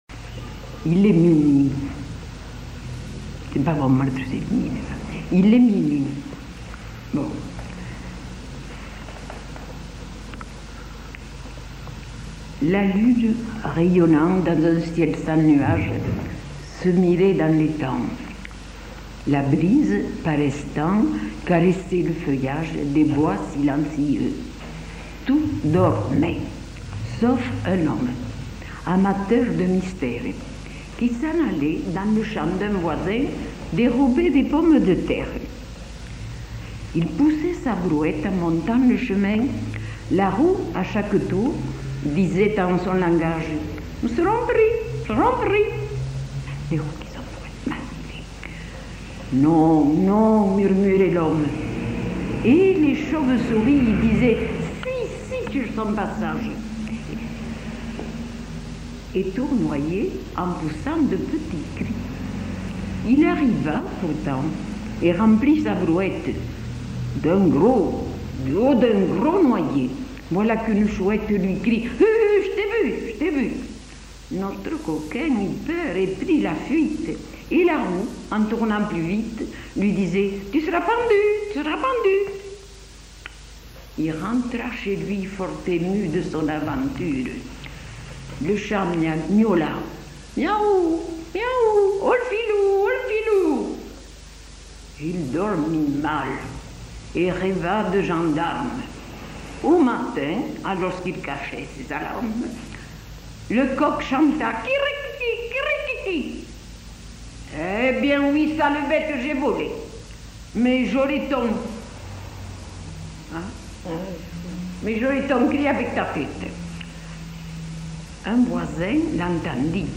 Aire culturelle : Haut-Agenais
Lieu : Cancon
Genre : conte-légende-récit
Type de voix : voix de femme
Production du son : récité
Classification : monologue ; mimologisme
Notes consultables : Récit avec mimologismes de la brouette, de la chauve-souris, de la chouette, la roue, du chat et du coq.